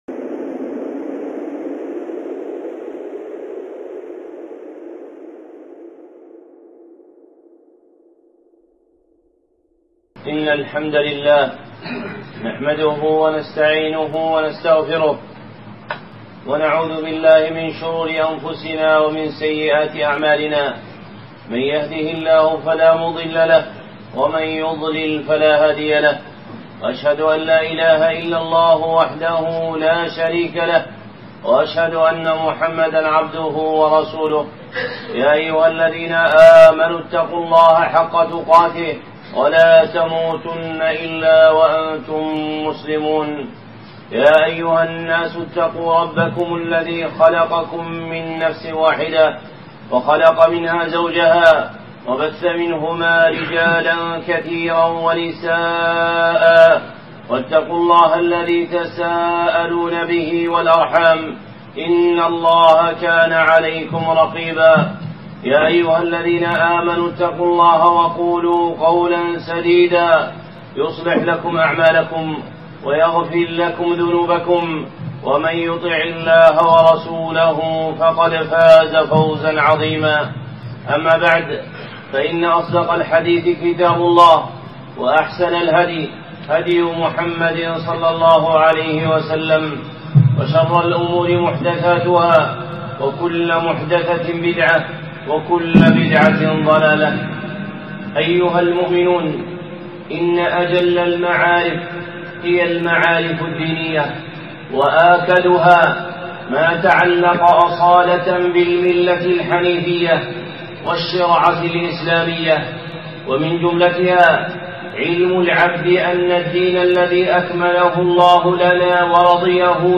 خطبة (شرح أم السنة)
الخطب المنبرية